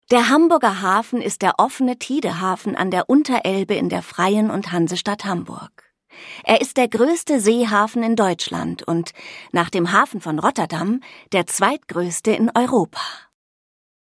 Jeune, Amicale, Distinctive, Polyvalente, Douce
Corporate
Elle peut parler en allemand neutre, mais aussi dans un dialecte nord-allemand.